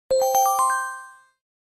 Alert_AscendingChimes.wma